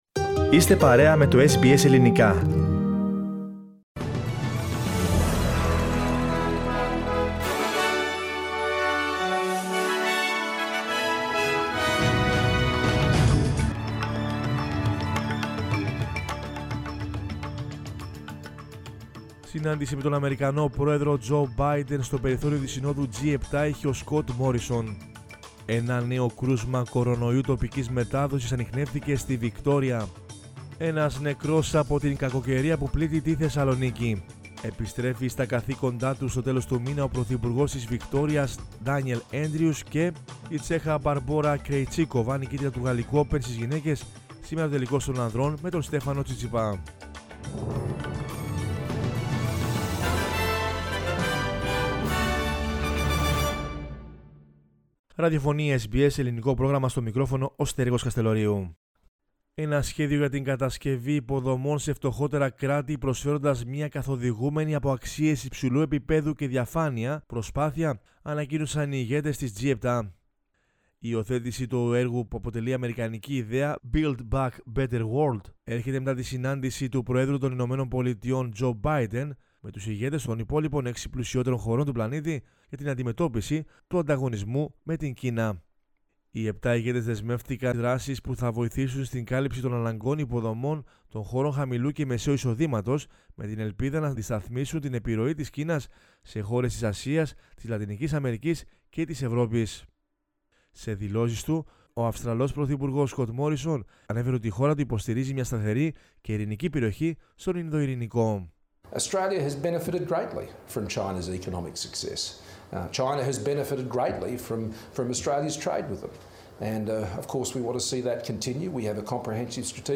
News in Greek from Australia, Greece, Cyprus and the world is the news bulletin of Sunday 13 June 2021.